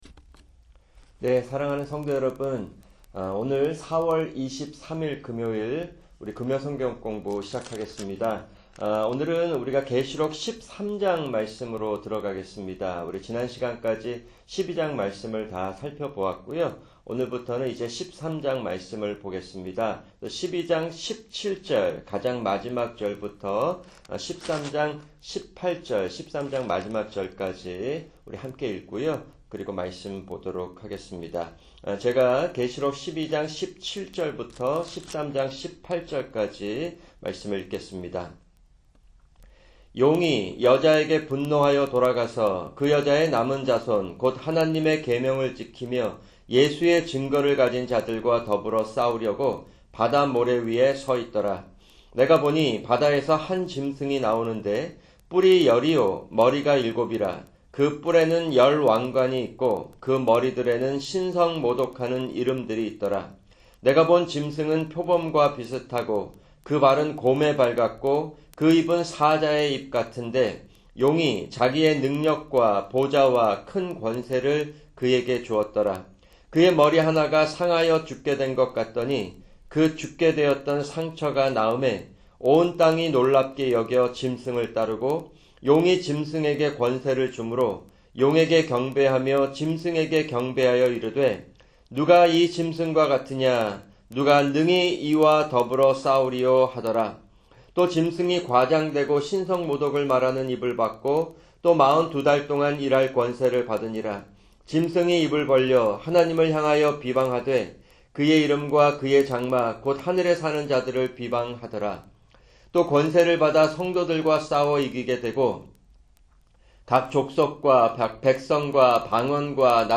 [금요 성경공부] 계시록 12:17-13:18(1)